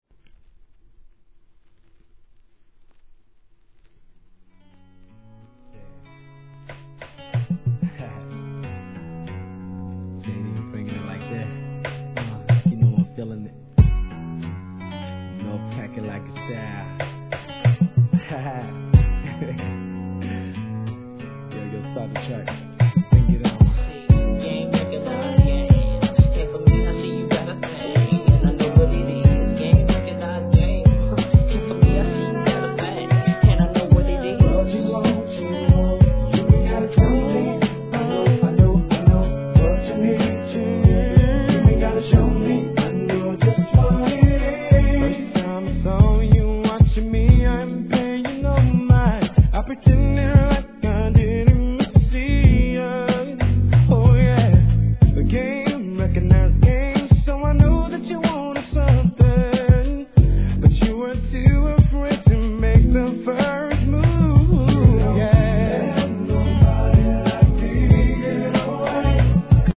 HIP HOP/R&B
本作も哀愁系でしっとりと歌い上げる一曲!